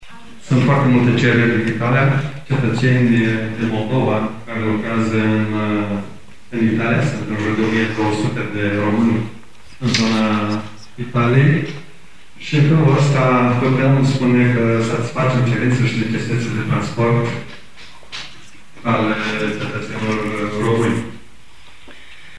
La ceremonie a fost present si presedintele Consiliului Judetean Iasi care a atras atentia atunci cind a vorbit de numarul romanilor care traiesc in Italia: 1.200